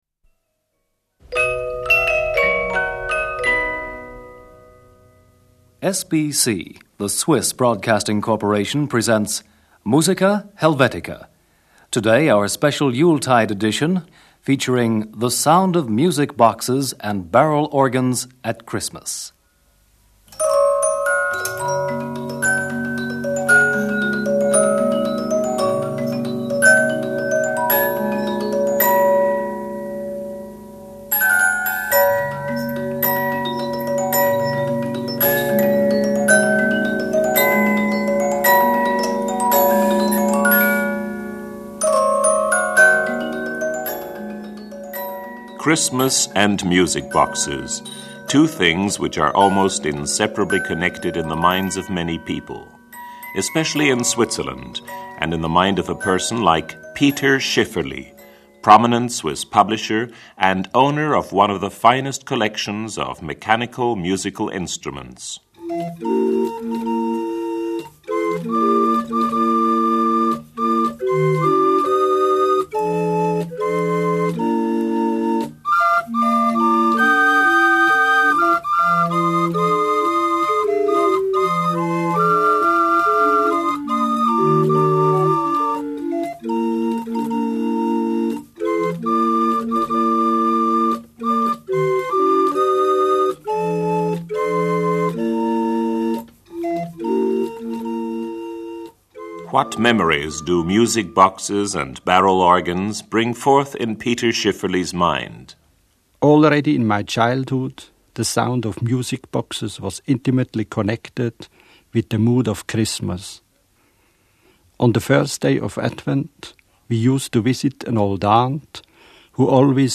Christmas In Switzerland. Music Boxes and barrel organs.
Music Box.
Barrel Organ.